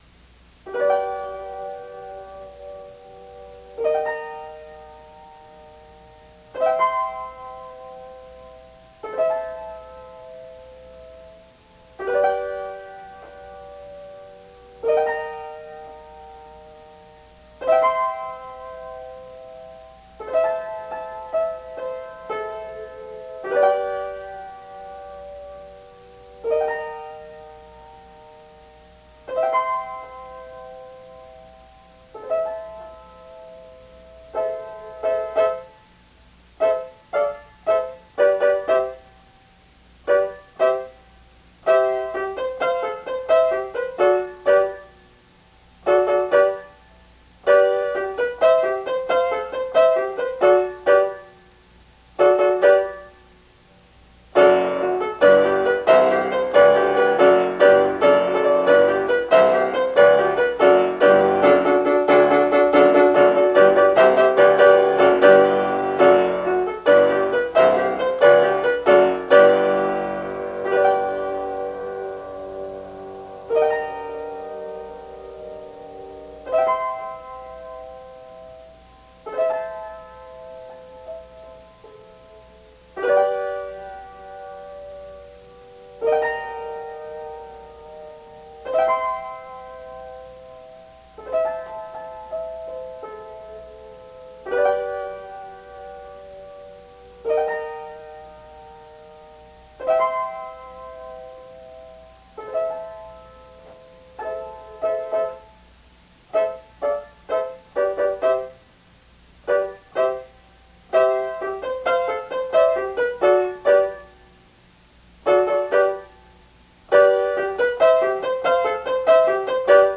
The following pieces consist of MIDI and/or MP3 files for the piano.
It was hard to get the midi to sound correct (I entered it by hand) but my live (MP3) recordings have mistakes - so you have a choice of accurate and uninspired, or inaccurate but at least not flat.